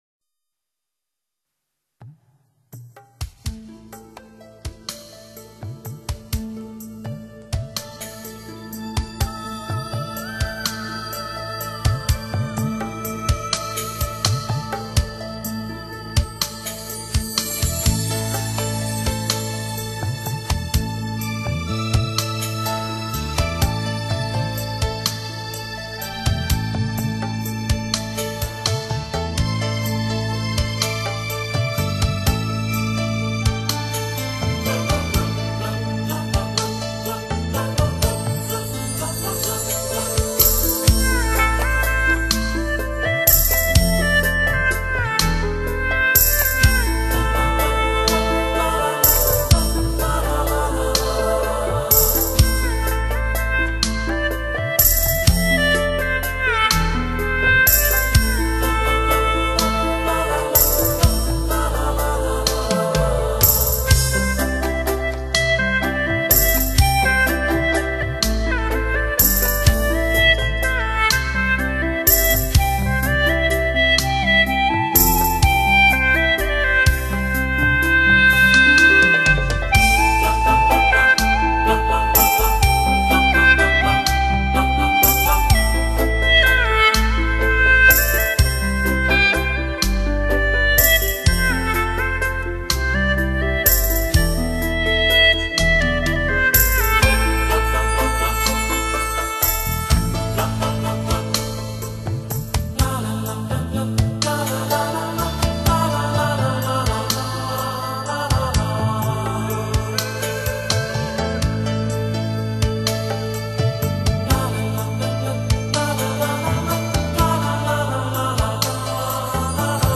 葫芦丝是云南少数民族乐器，以前主要流传于傣，彝等民族中，其音色轻柔细腻，悠远緾绵。